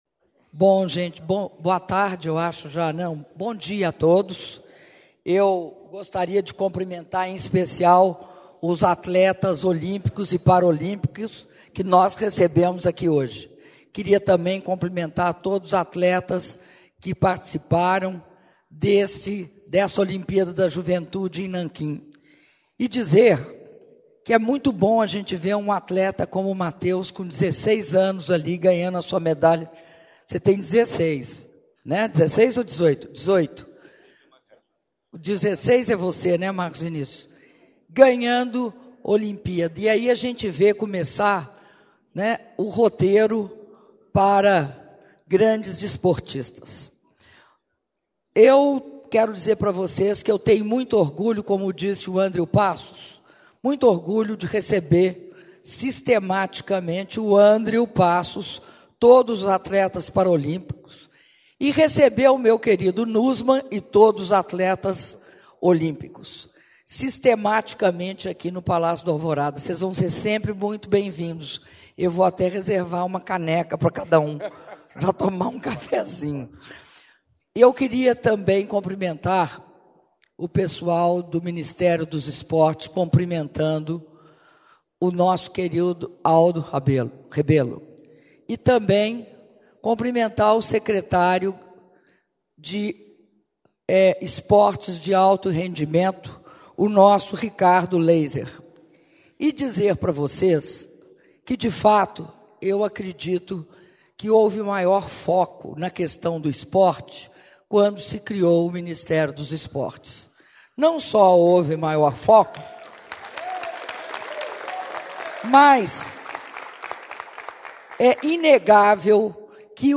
Áudio das palavras da Presidenta da República, Dilma Rousseff, durante encontro com jovens atletas olímpicos e paraolímpicos - Brasília/DF (07min31s)